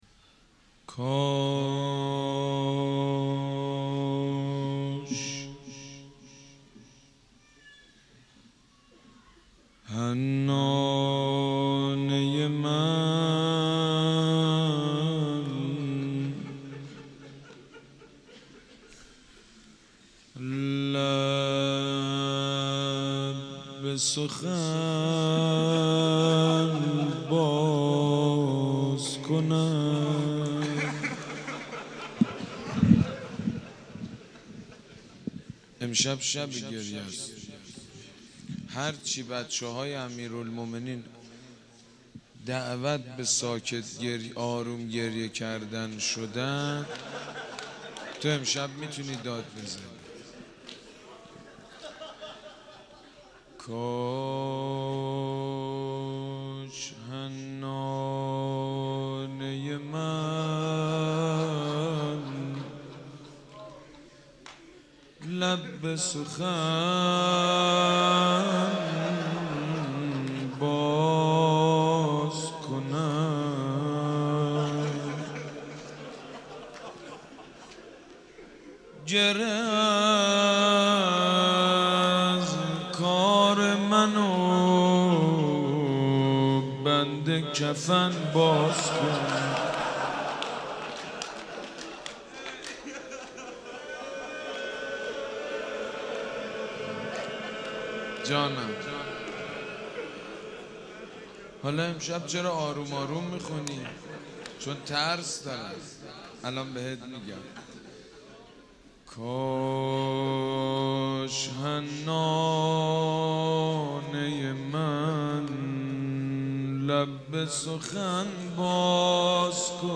دانلود مداحی فاطمیه 1394
روضه، شهادت حضرت زهرا(س)